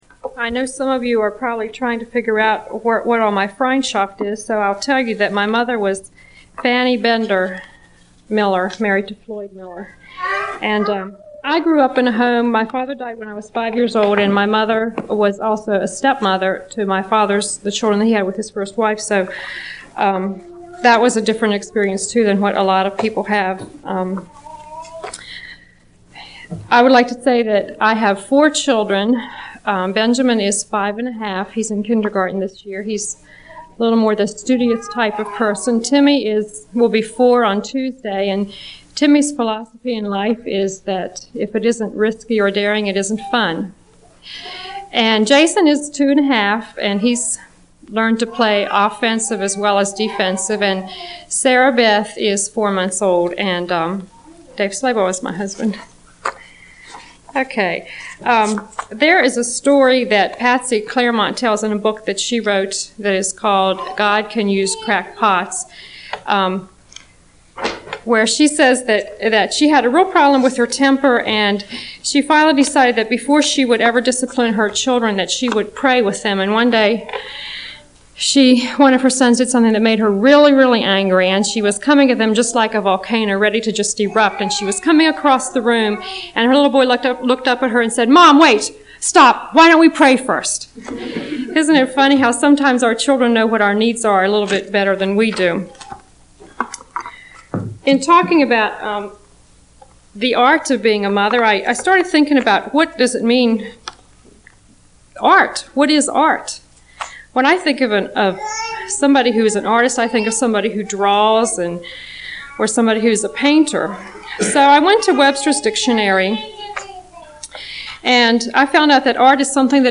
Home » Lectures » The Art of Being a Mother